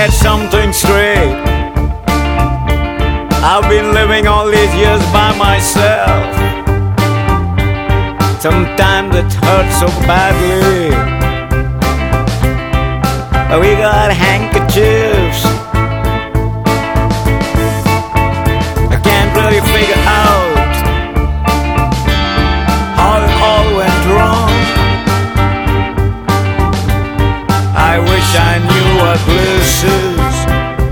crooneur indien